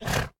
Minecraft Version Minecraft Version snapshot Latest Release | Latest Snapshot snapshot / assets / minecraft / sounds / mob / horse / donkey / idle1.ogg Compare With Compare With Latest Release | Latest Snapshot